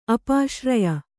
♪ apāśraya